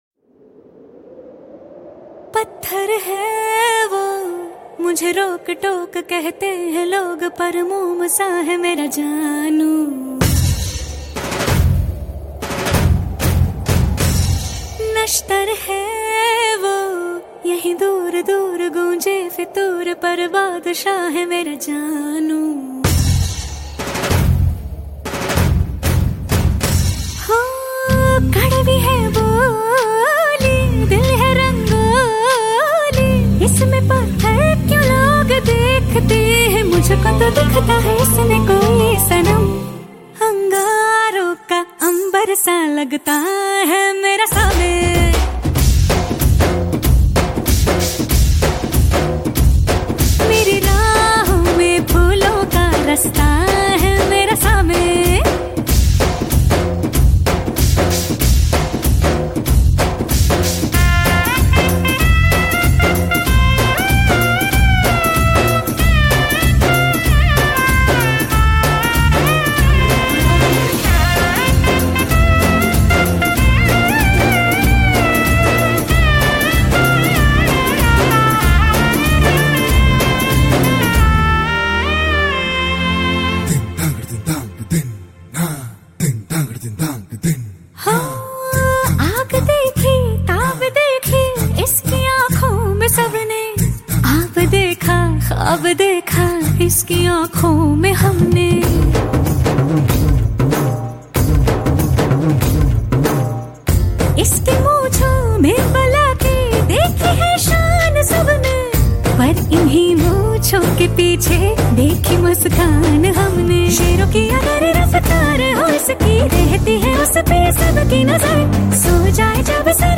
intense beats